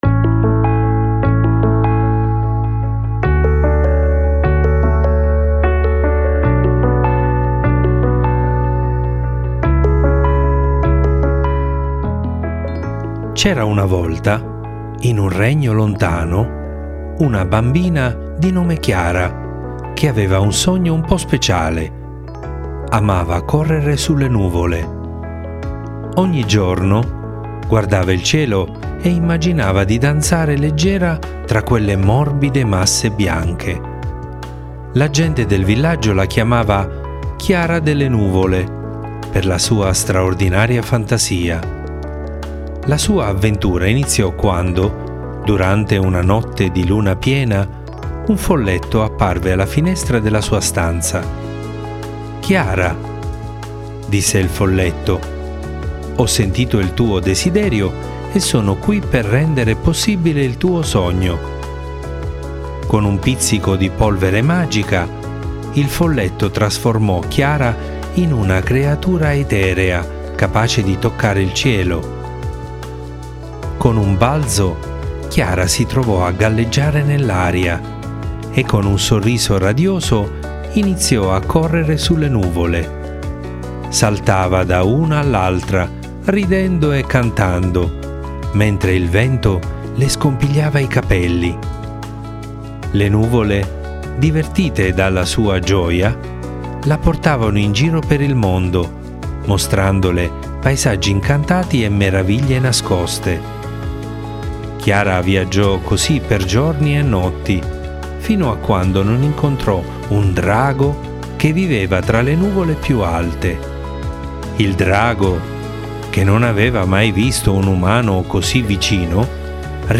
Leggi e ascolta la fiaba di “Chiara delle nuvole”